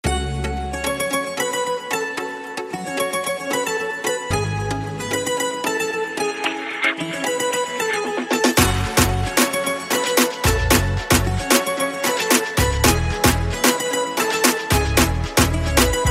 نغمة صوت الطيور
Message Tones
latest , new , sms , nokia , top , popular , bird , chirp ,